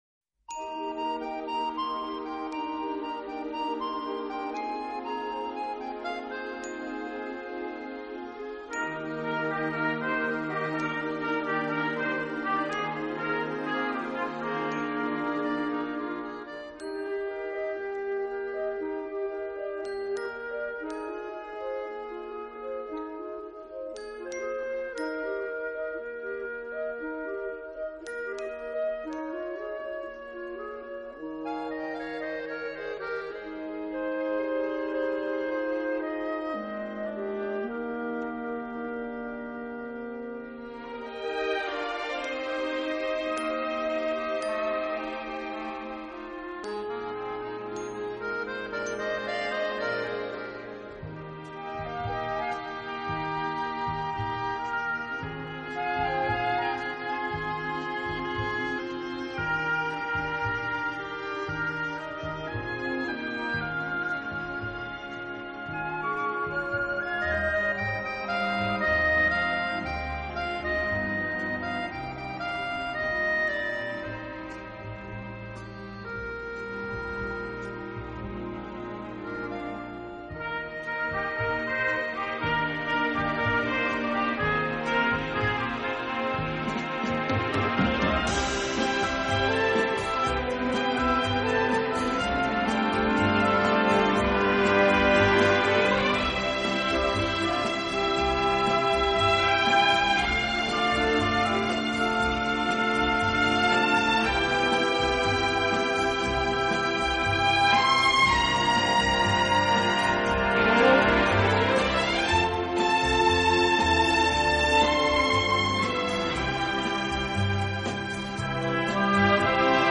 录制方式:ADD
于1975年夏在法国巴黎录制
这个乐团的演奏风格流畅舒展，
旋律优美、动听，音响华丽丰满。